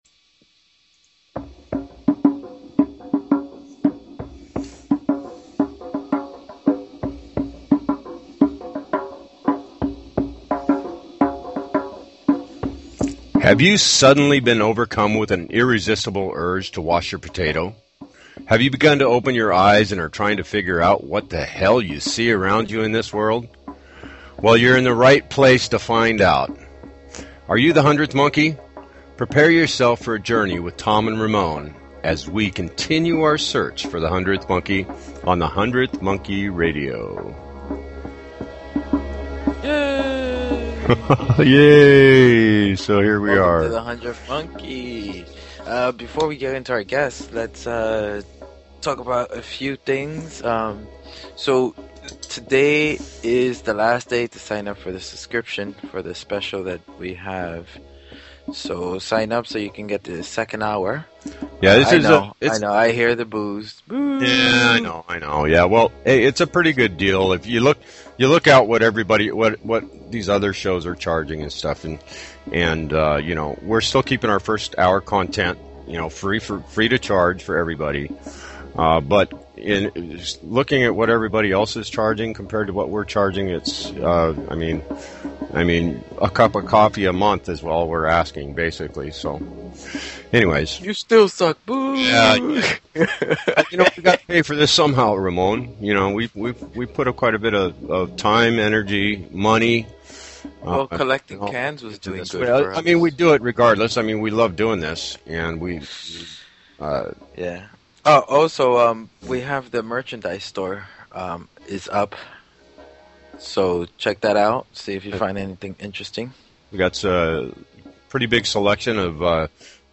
Talk Show Episode, Audio Podcast, The_Hundredth_Monkey_Radio and Courtesy of BBS Radio on , show guests , about , categorized as